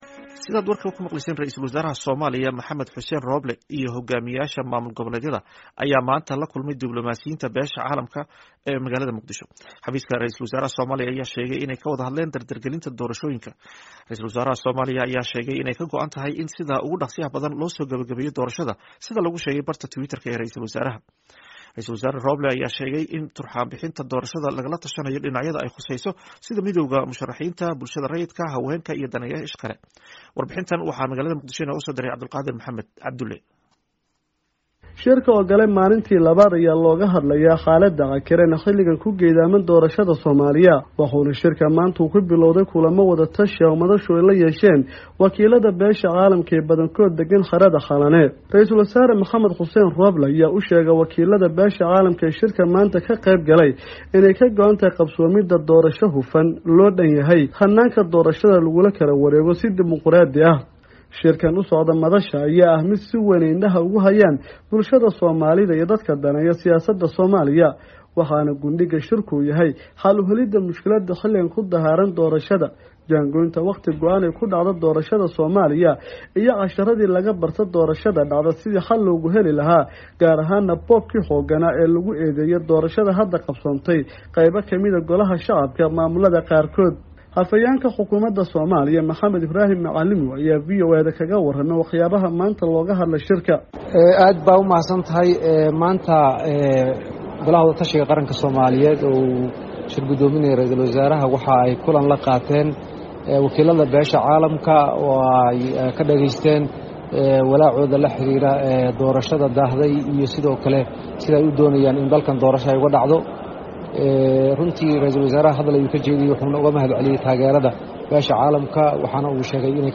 Warbixintan waxaa Muqdishu inooga soo diray